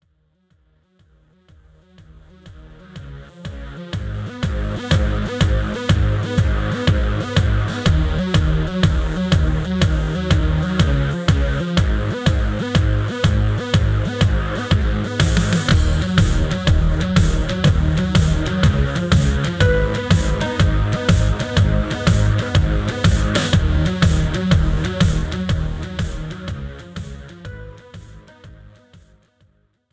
Pop , Rock